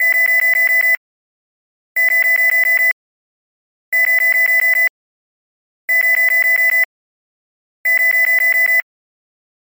Звуки фантастики